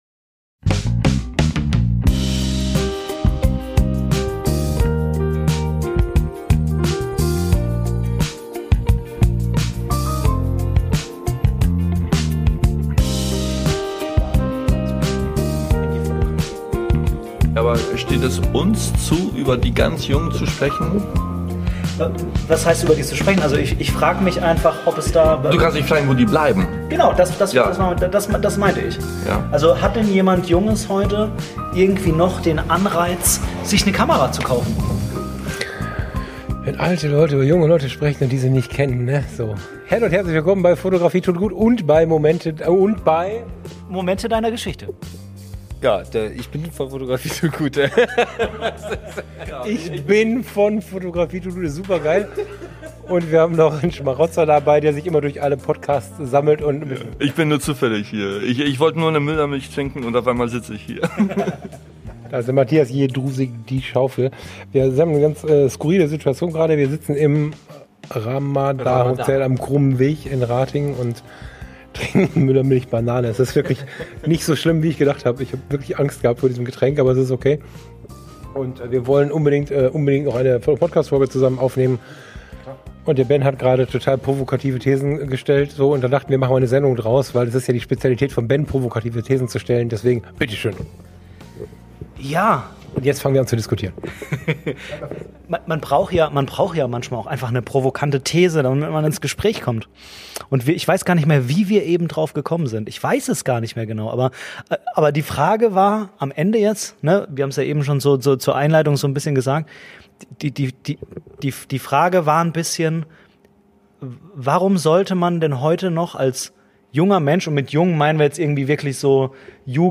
Talk-Folge mit zwei Gästen
In der Lobby eines Hotels in Ratingen sitzen wir zusammen und sinnieren über den Nachwuchs in der Fotografieszene. Und darüber, mit welcher Kamera ein Einstieg in unser Lieblings-Hobby besonders erfüllend sein könnte.